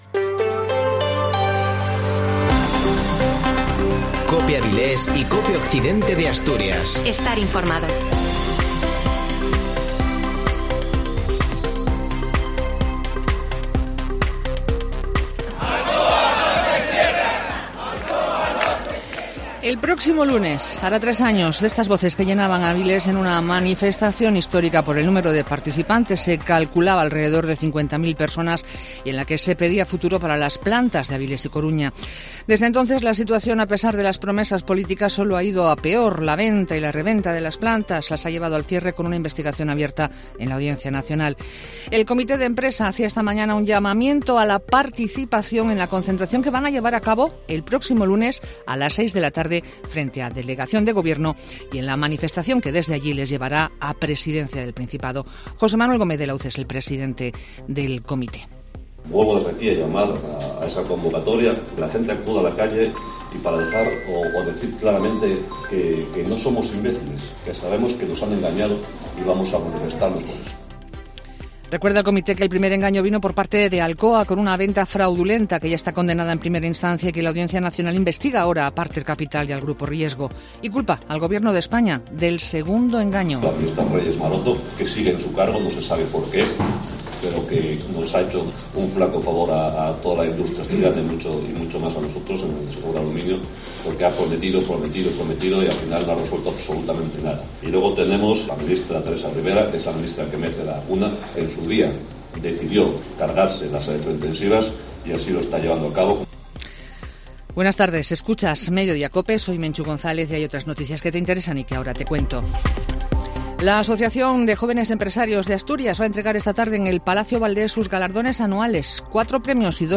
Informativo Mediodía en Cope Avilés y Occidente 03-11-21